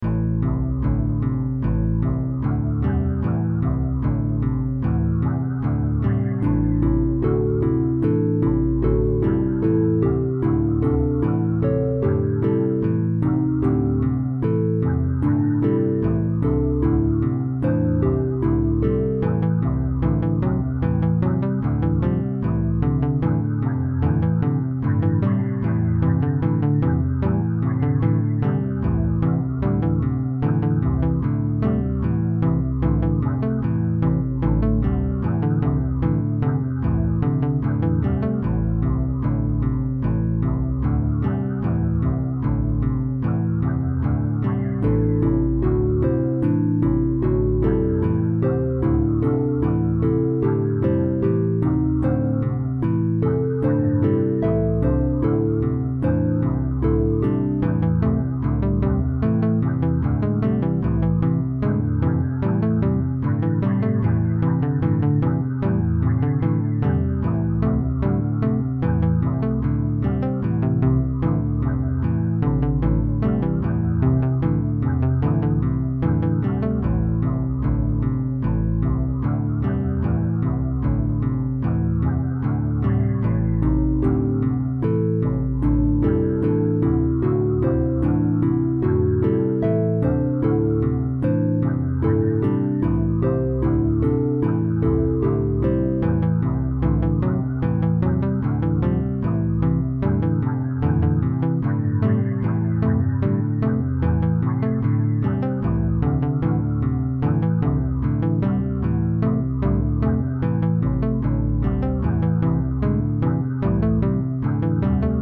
A fun little tune that can be used in any game or project.